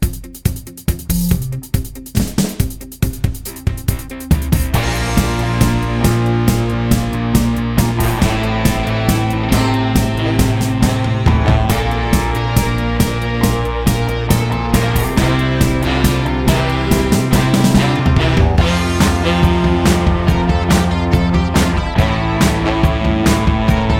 no Backing Vocals Glam Rock 4:12 Buy £1.50